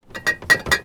R - Foley 1.wav